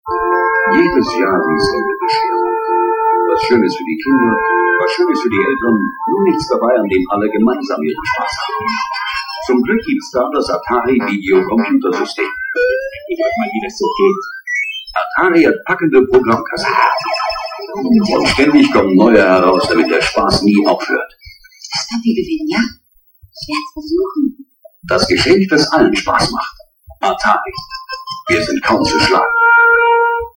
Atari - German Audio Commercial
Two great audio commercials from German TV.
Atari commercial (1982)
atari-82-commercial.mp3